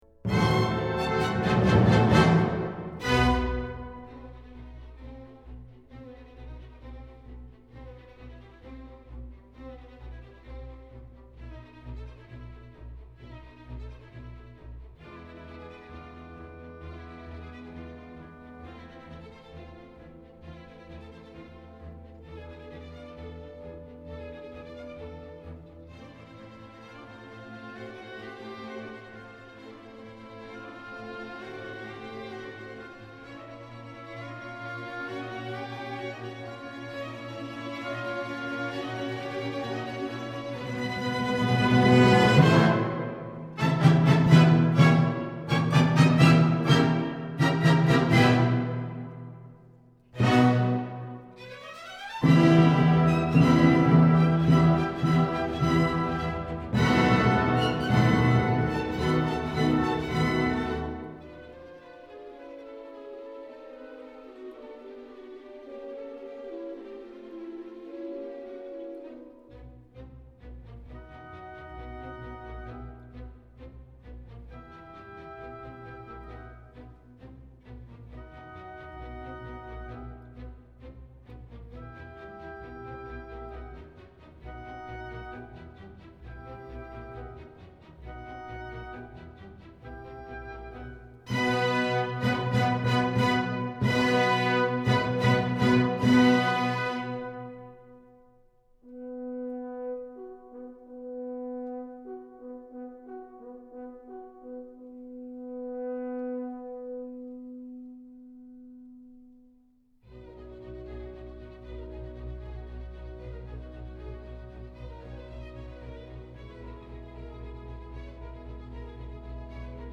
Clásica